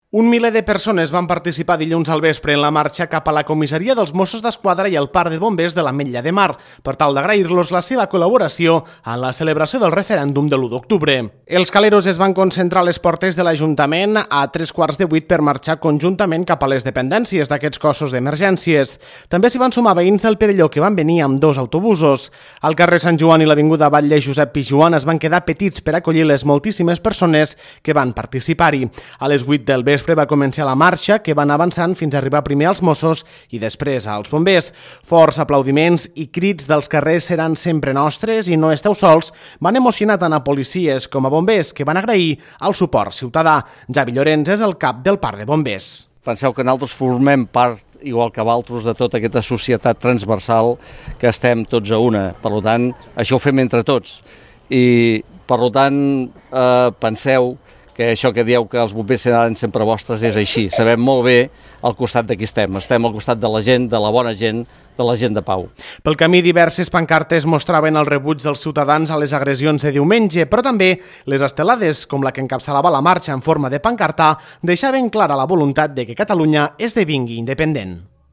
Forts aplaudiments i crits d’«els carrers seran sempre nostres» i «no esteu sols» van emocionar tant a policies com bombers, que van agrair el suport ciutadà.